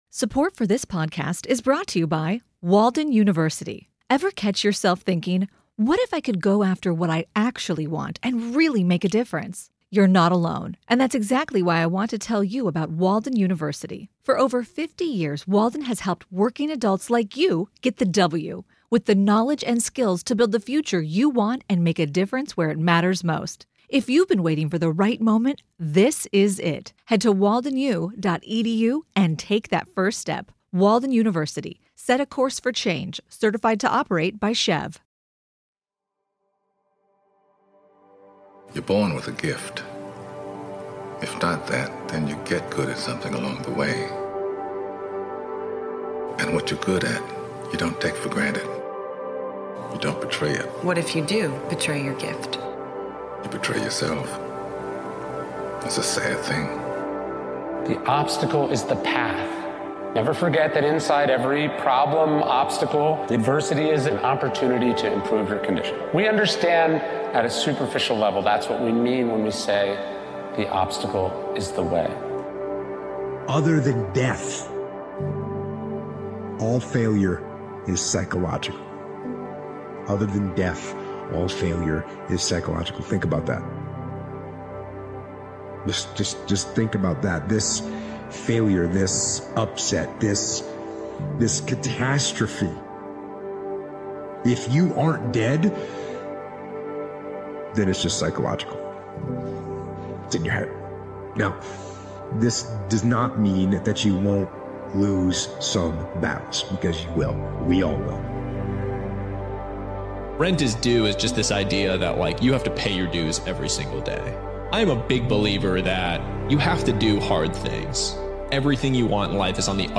Through a powerful collection of motivating speeches, you’ll learn what it takes to become the person you aspire to be. Discover the mindset, habits, and actions that will propel you forward and help you stand out in a crowd.